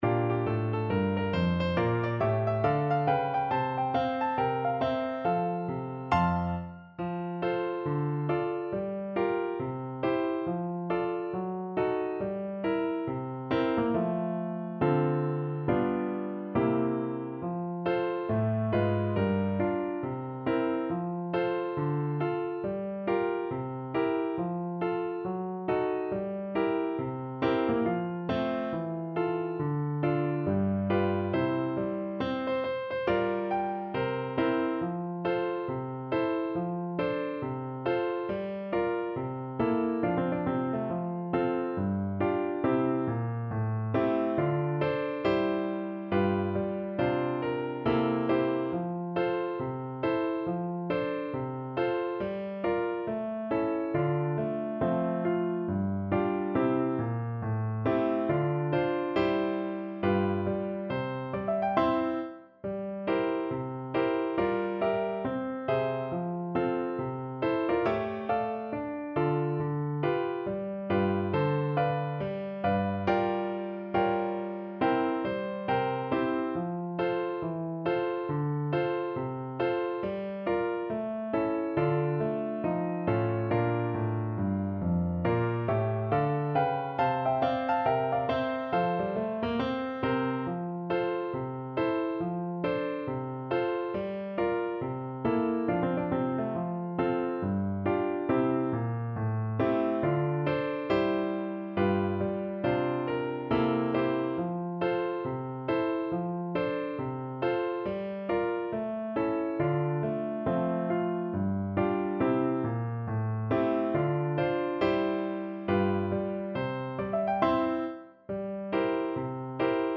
4/4 (View more 4/4 Music)
With a swing =c.69
Pop (View more Pop Clarinet Music)